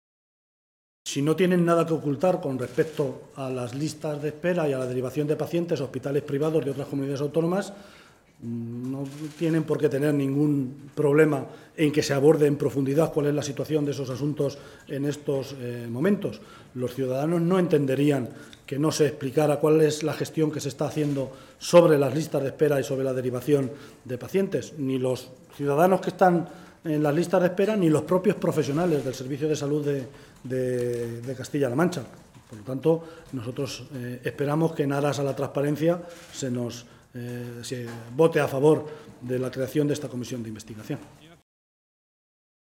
Unos minutos después de dejar registrada esa iniciativa, era el propio portavoz socialista en la Cámara autonómica, José Luis Martínez Guijarro, el que comparecía ante los medios de comunicación para explicar los motivos que habían llevado a los socialistas a exigir esta comisión de investigación y los objetivos que persigue la misma.